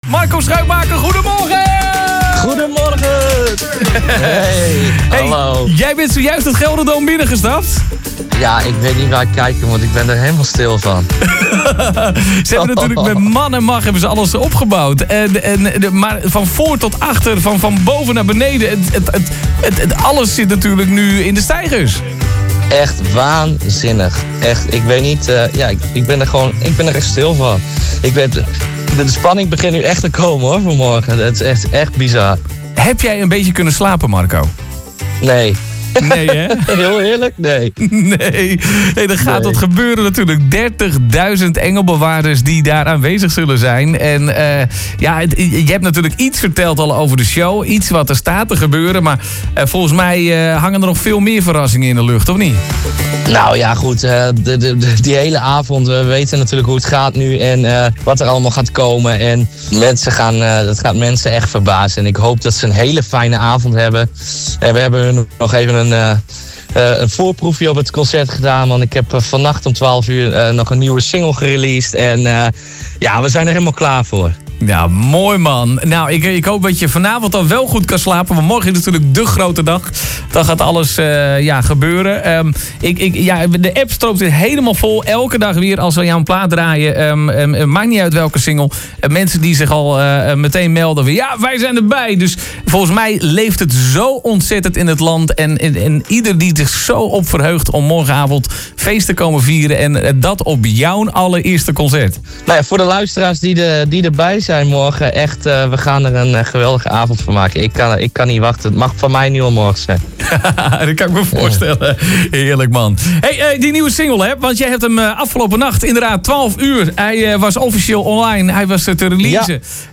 Luister hier onder naar het telefoongesprek!